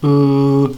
listen), which is slightly lower.
Near-close_central_rounded_vowel2.ogg.mp3